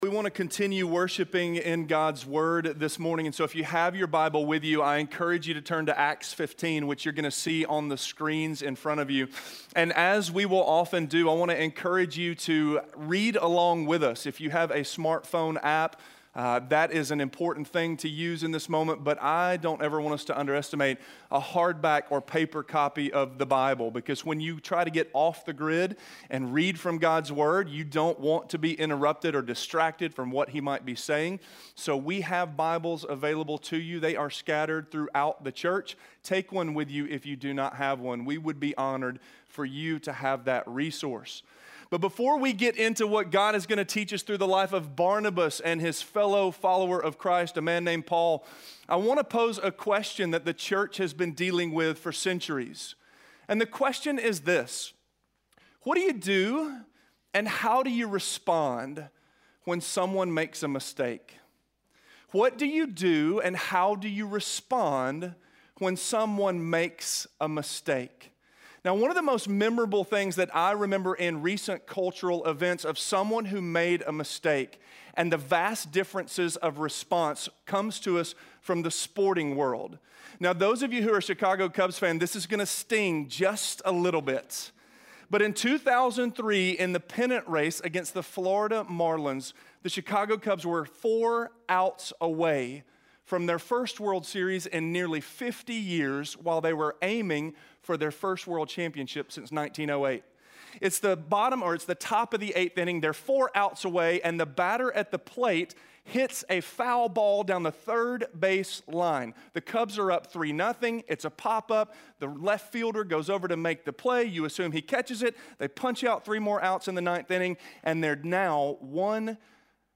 Just Like Barnabas: Defending - Sermon - Avenue South